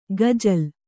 ♪ gazal